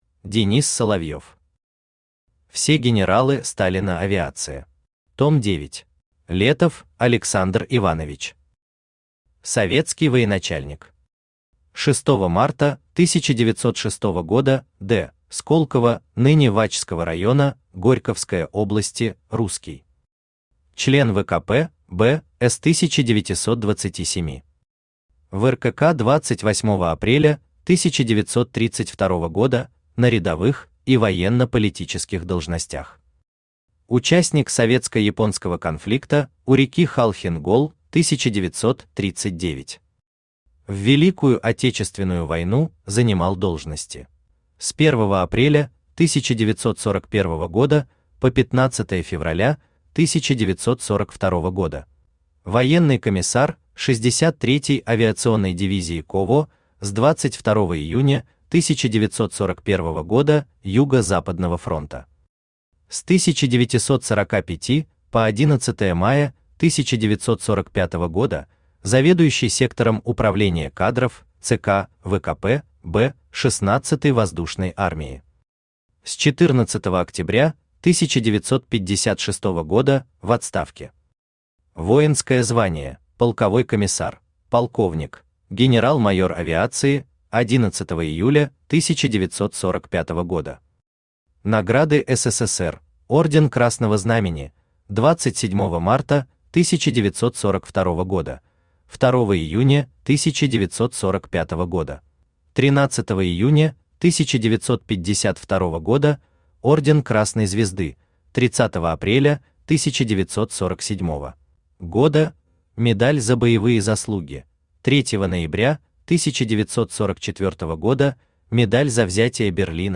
Том 9 Автор Денис Соловьев Читает аудиокнигу Авточтец ЛитРес.